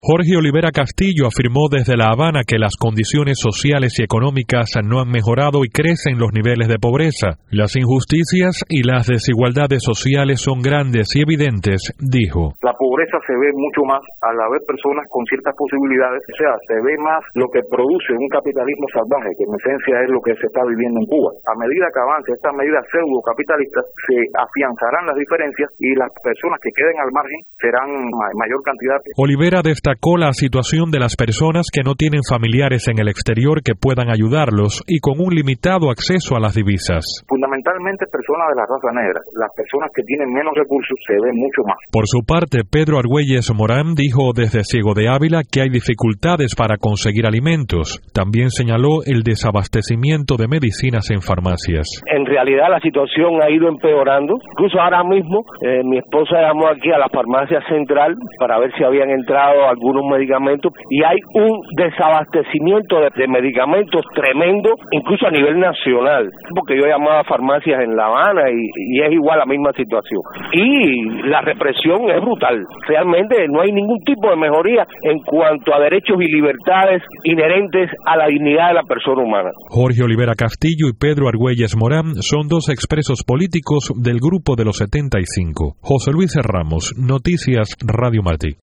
conversó con los comunicadores independientes y tiene el reporte.